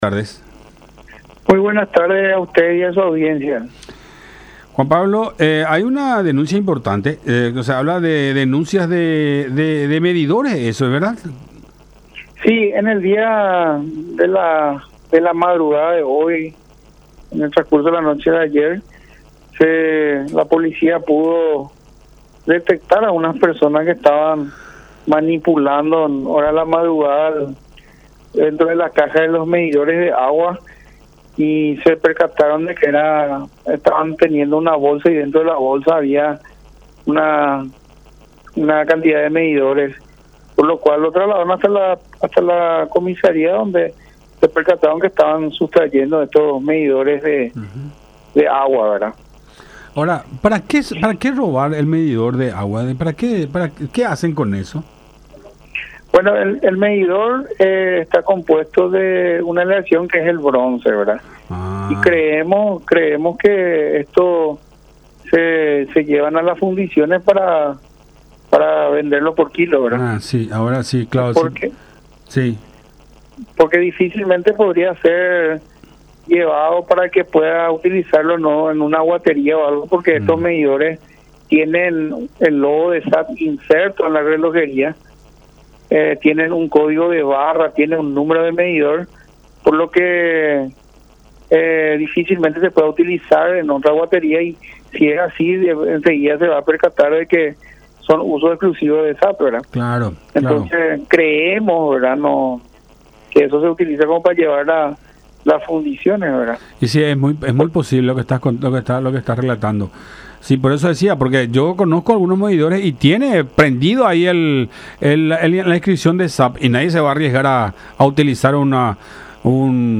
en diálogo con La Unión.